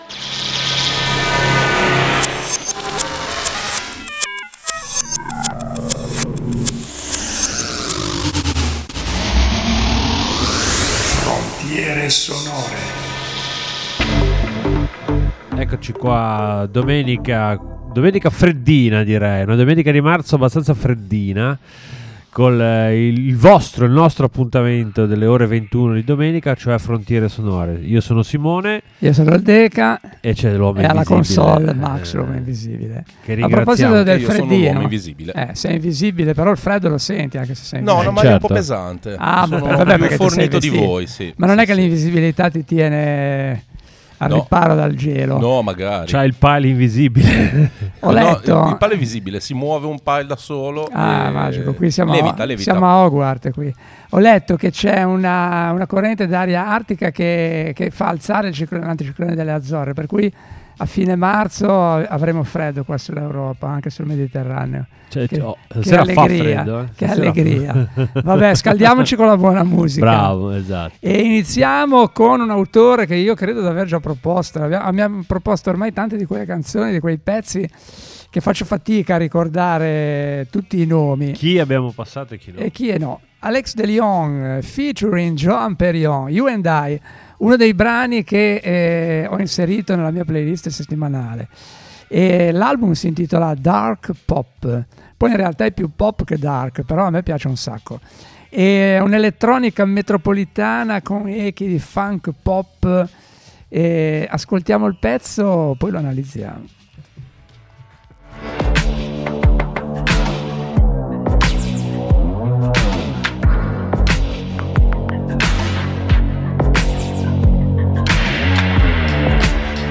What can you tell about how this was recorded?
Conducono in studio